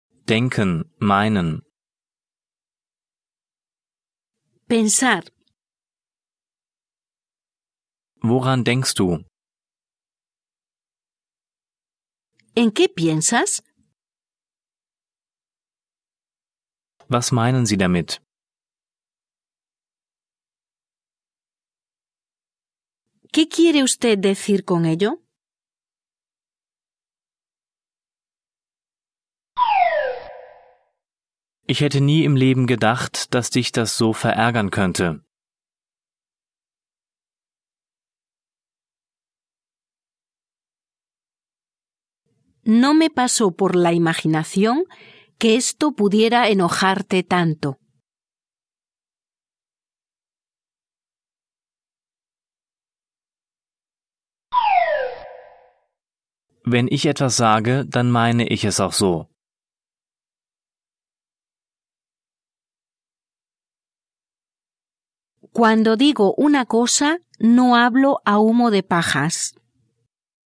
Der Audiotrainer Grundwortschatz Spanisch ist lehrbuchunabhängig und enthält über 2000 Wörter und Beispielsätze auf 4 Audio/MP3-CDs mit ca. 300 Minuten Spielzeit. Es sind damit die Wörter erfasst, die man häufig braucht.Er ist zweisprachig aufgebaut (Deutsch - Spanisch), nach Themen geordnet und von Muttersprachlern gesprochen. Übersetzungs- und Nachsprechpausen sorgen für die Selbstkontrolle.